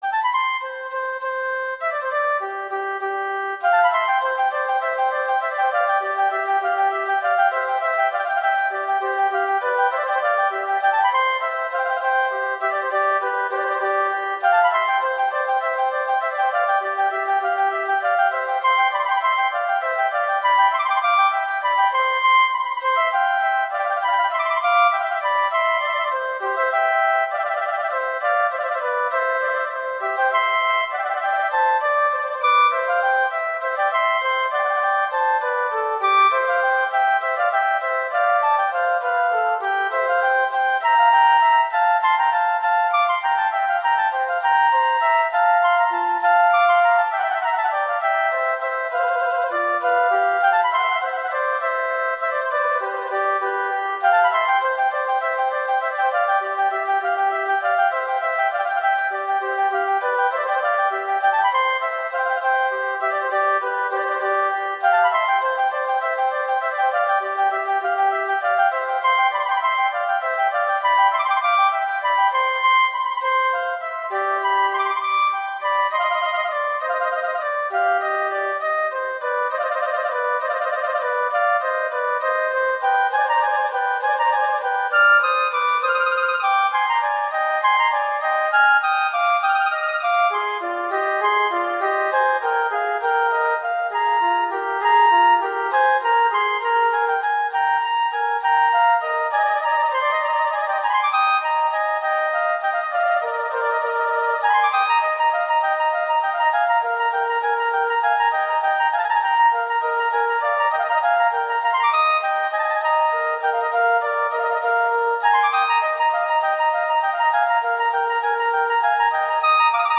classical, children